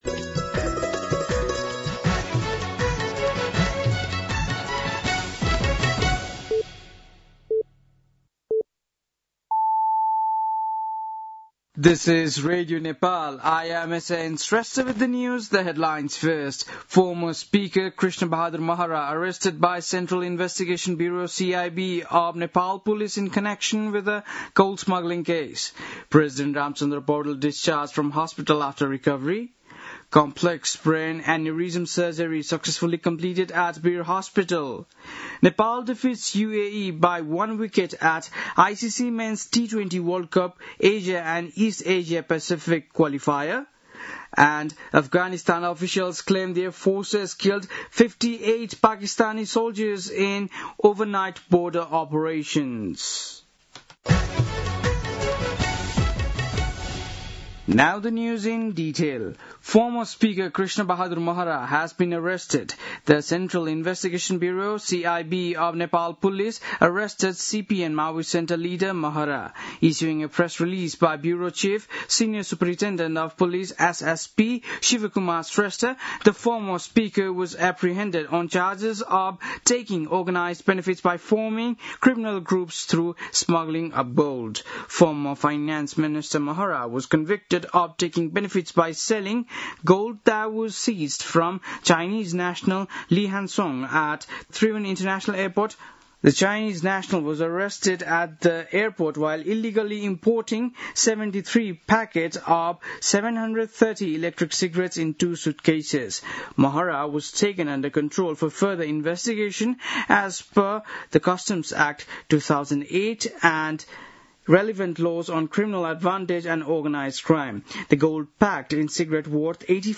बेलुकी ८ बजेको अङ्ग्रेजी समाचार : २६ असोज , २०८२
8-pm-english-news-6-26.mp3